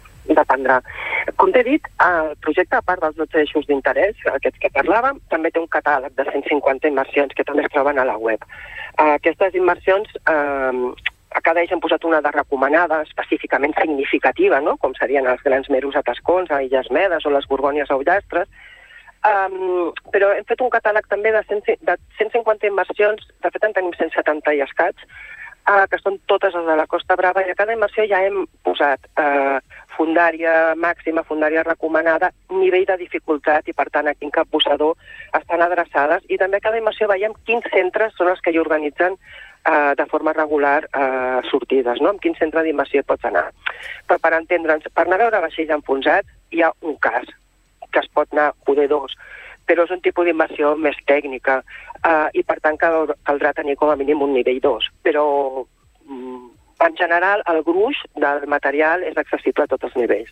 Entrevistes SupermatíNotícies